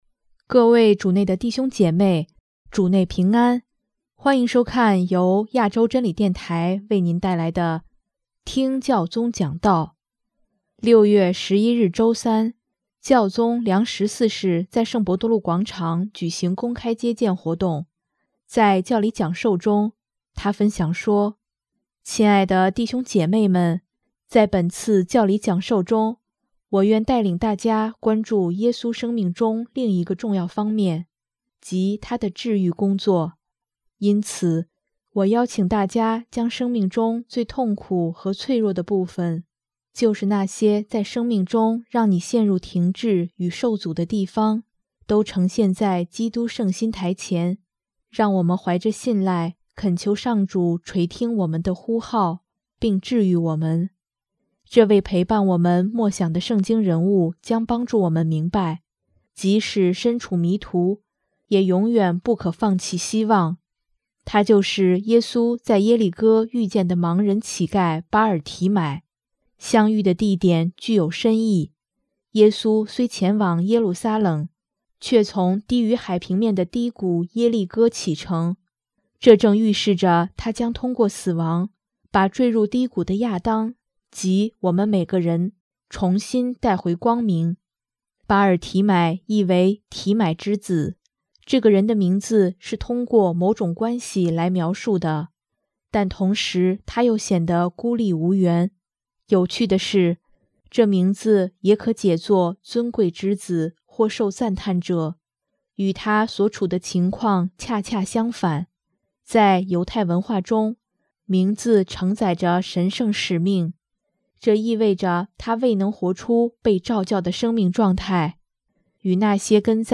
6月11日周三，教宗良十四世在圣伯多禄广场举行公开接见活动。